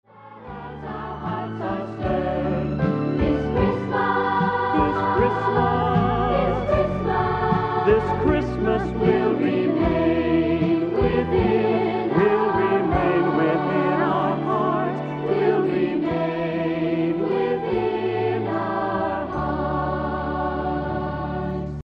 A lovely Christmas song for children and adults